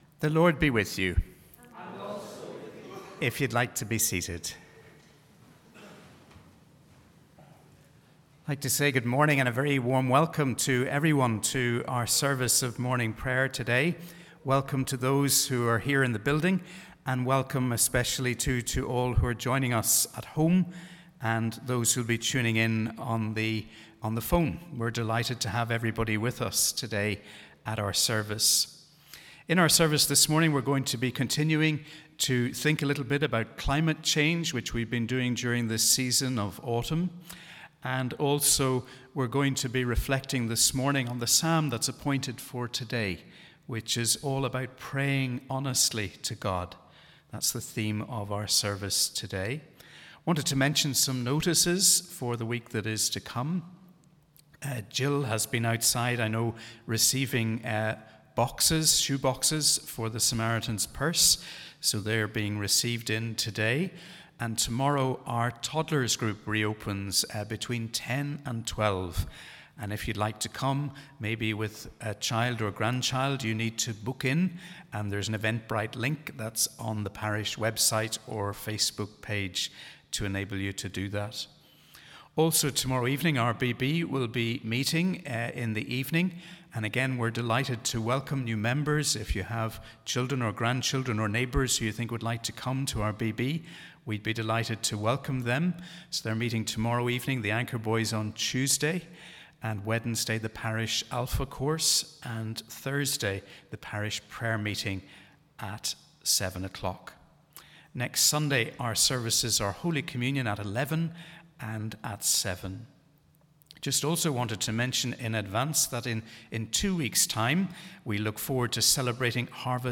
Wherever you are, we warmly welcome you to our service for the 19th Sunday after Trinity.